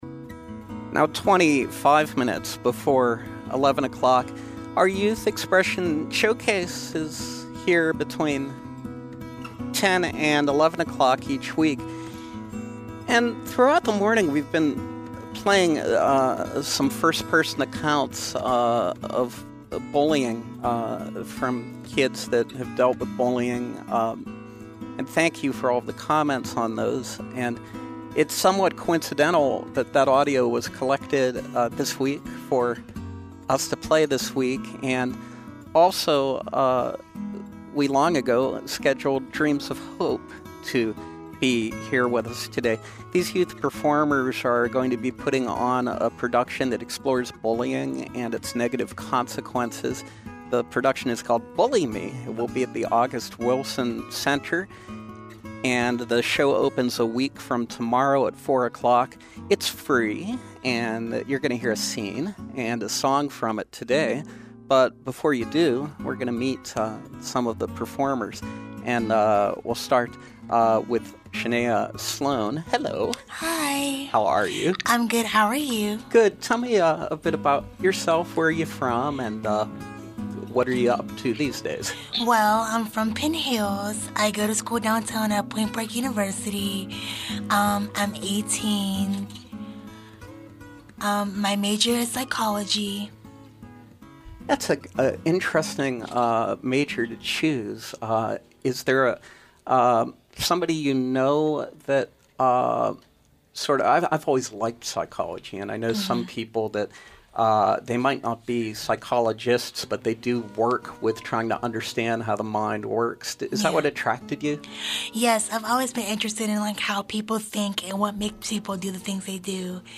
Through spoken word, movement, drama, and song, Dreams of Hope youth performers explore bullying and its negative consequences in their production of Bully Me. We welcome members of the cast who will preview a scene, live on SLB.